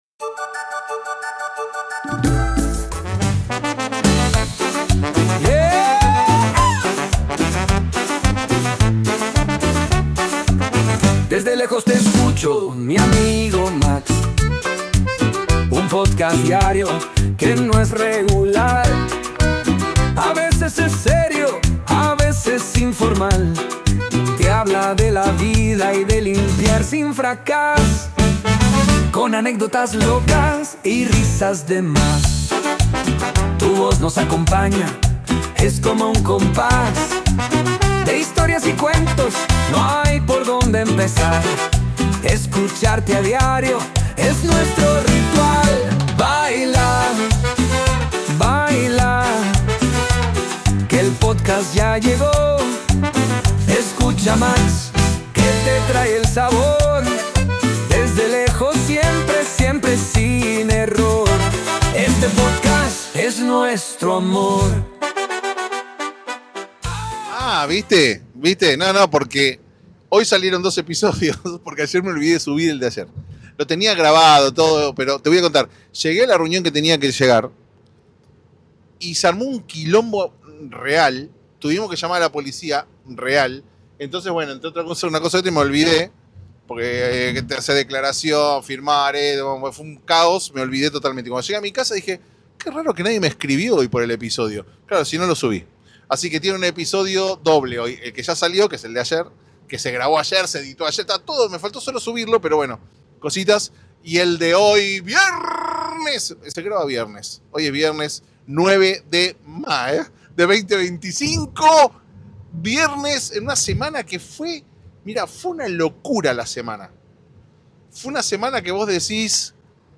Un día de locos pero, como me gustan los locos, entonces está todo bien! Un episodio increíble (literal) Incluye canción y todo!